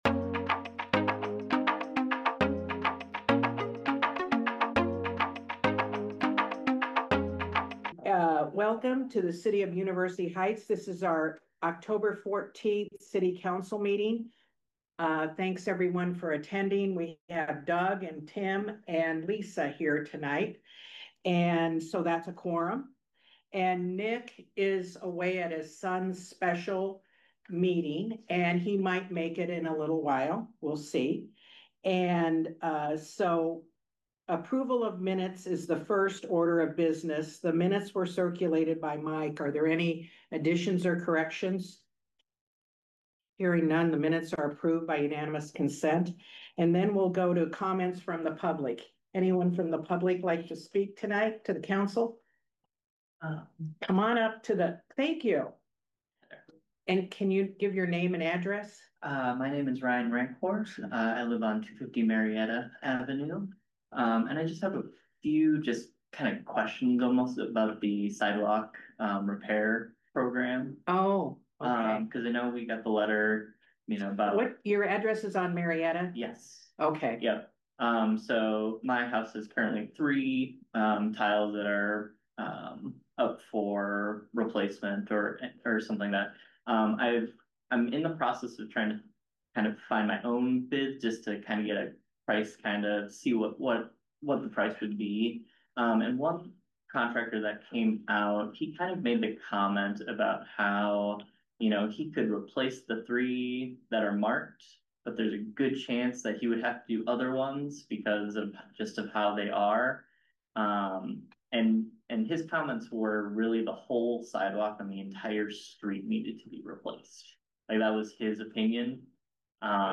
University Heights City Council Meeting of 10/14/25
The monthly University Heights City Council Meeting.